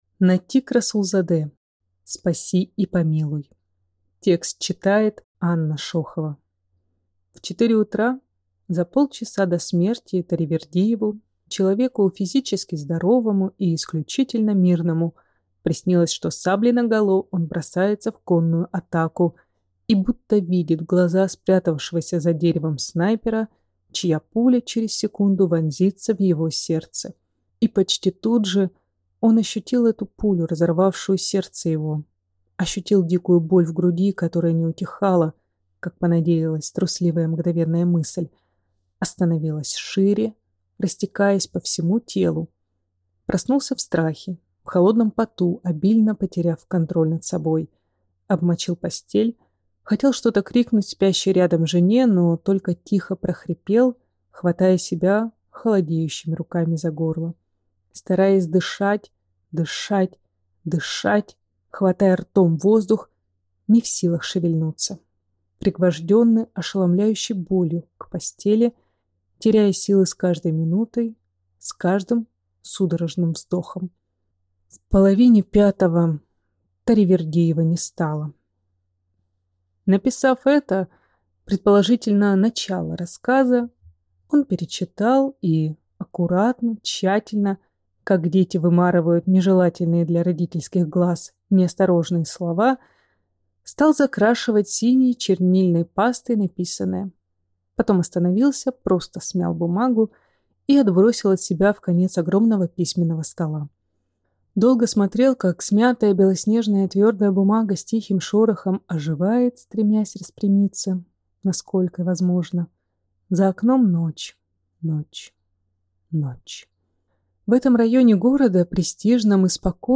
Аудиокнига Спаси и помилуй!